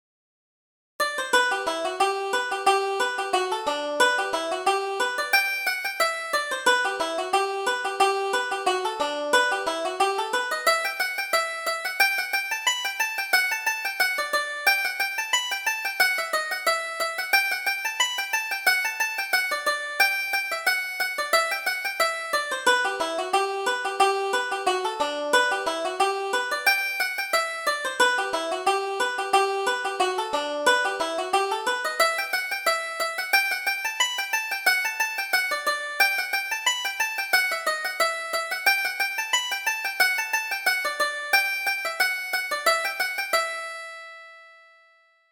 Reel: Big Pat's Reel